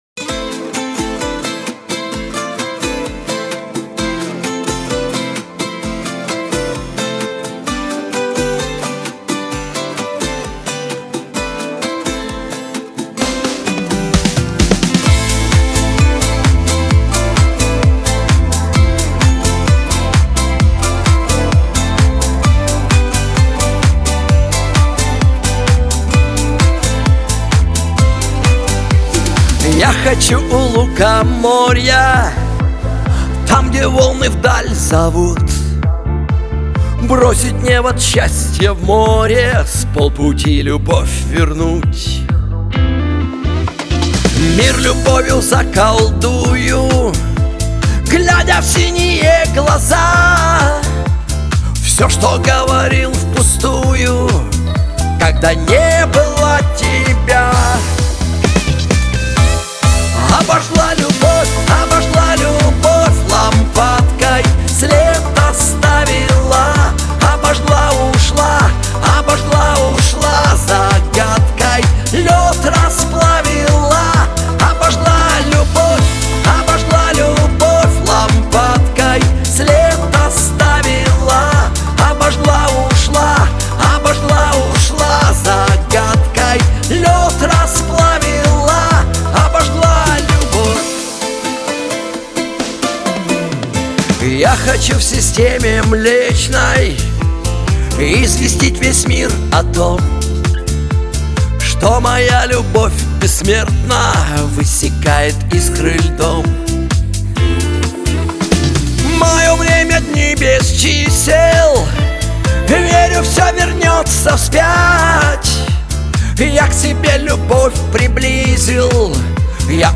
pop
Шансон , диско
эстрада